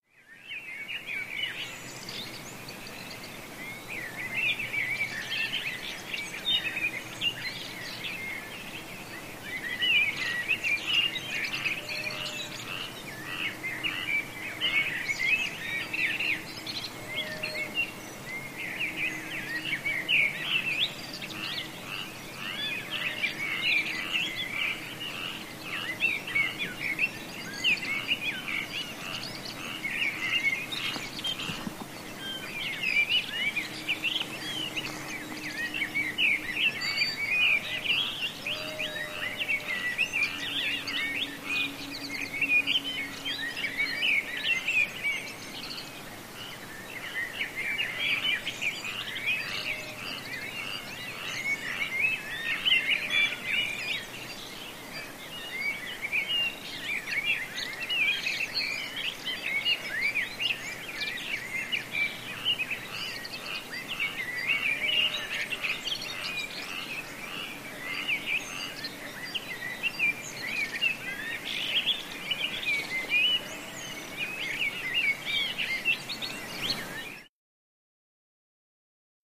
Early Morning Birds And Frogs